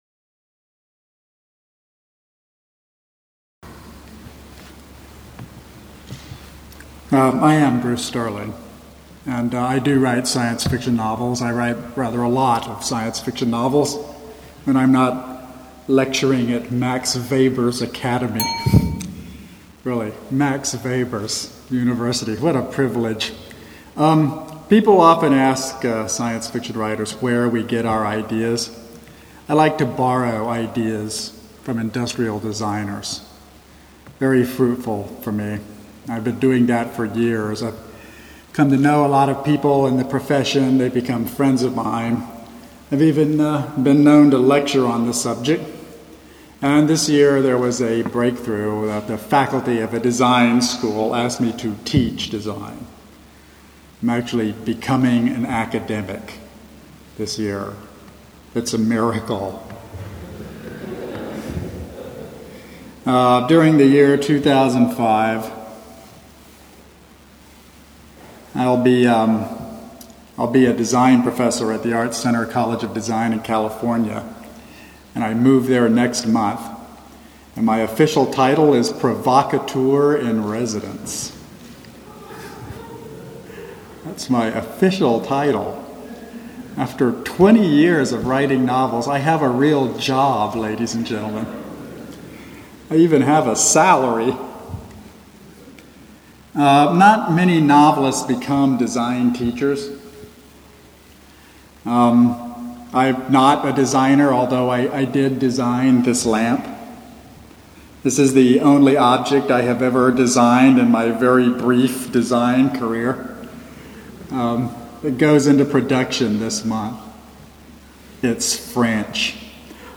A speech Bruce Sterling gave at Max Weber's academy in December, 2004.
maxweberlecture56991.mp3